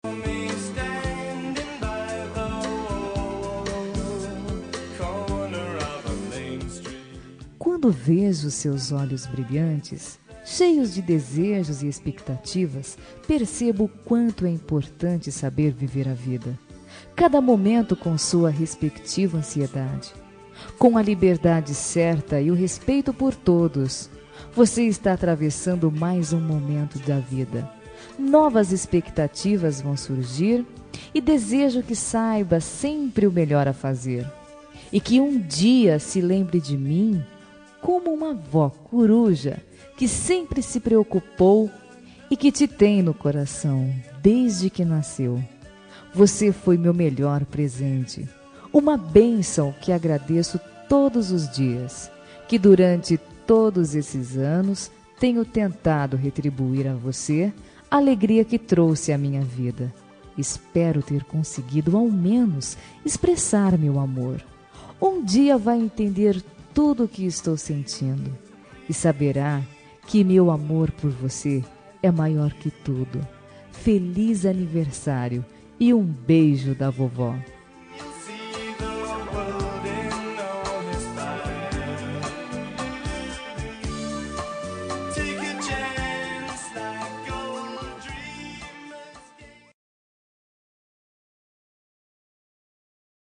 Aniversário de Neta – Voz Feminina – Cód: 131029